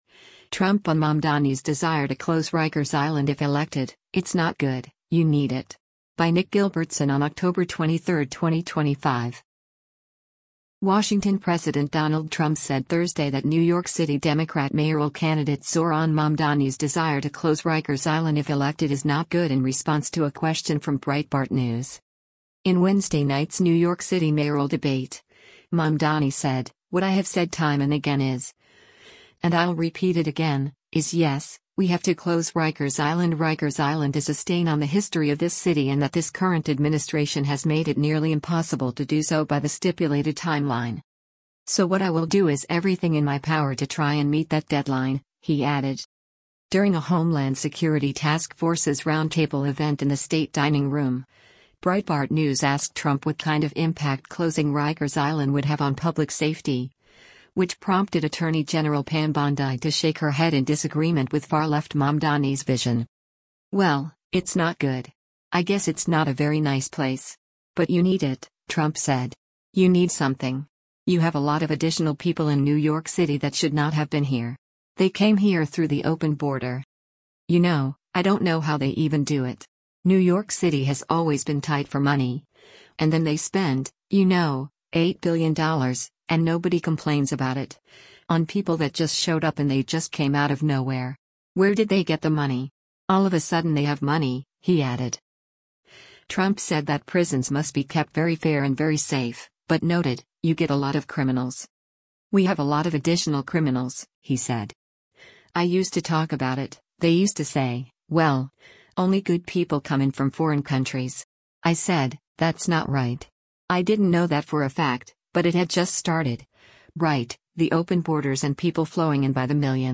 During a Homeland Security Task Forces roundtable event in the State Dining Room, Breitbart News asked Trump what kind of impact closing Rikers Island would have on public safety, which prompted Attorney General Pam Bondi to shake her head in disagreement with far-left Mamdani’s vision.